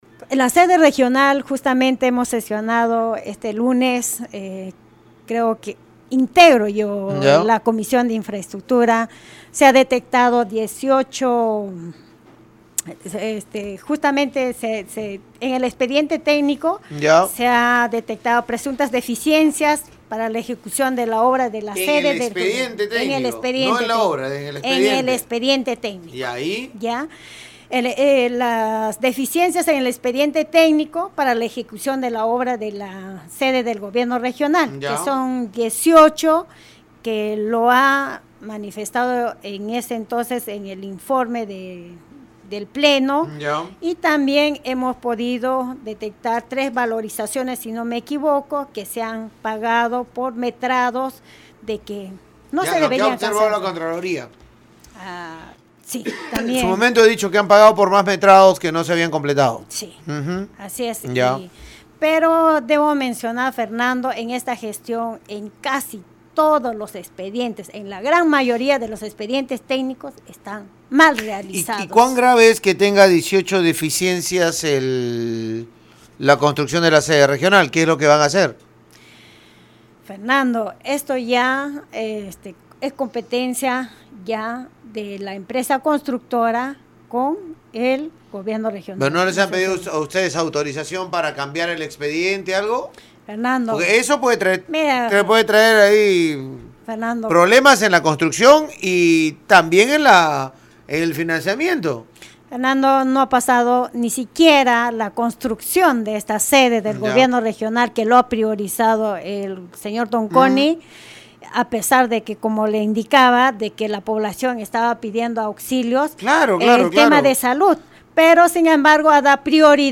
La consejera regional Luz Huacapaza en conversación con Radio Uno evidenció que seis de sus colegas han mostrado respaldo a la gestión Tonconi protegiendo a la autoridad y funcionarios de confianza designados por este.